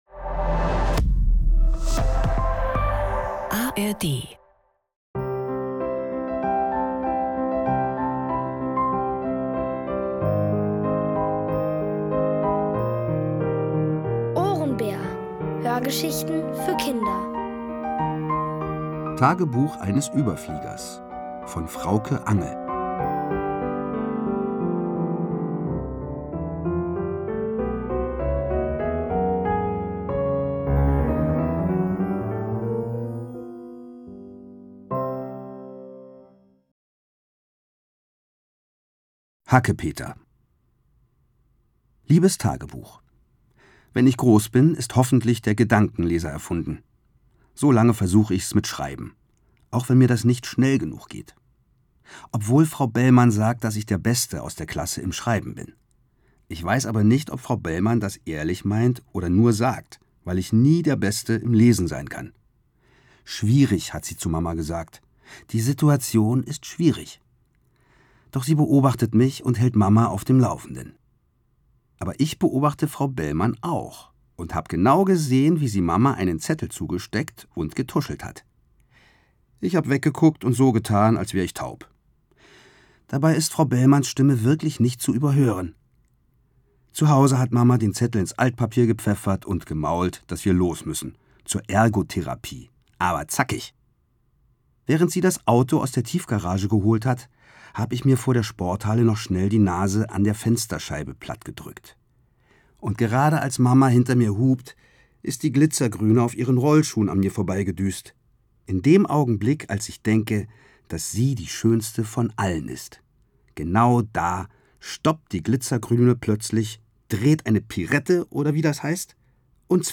Alle 5 Folgen der OHRENBÄR-Hörgeschichte: Tagebuch eines Überfliegers von Frauke Angel.